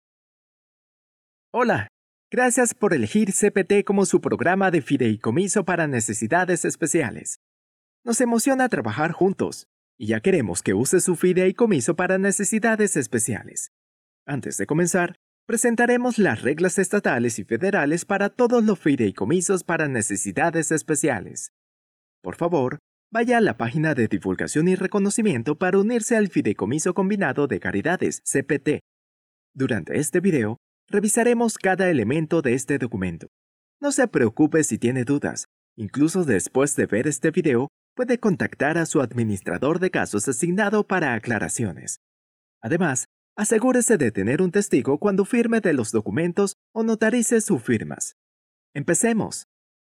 Sprechprobe: eLearning (Muttersprache):
I will recording spanish male voice over.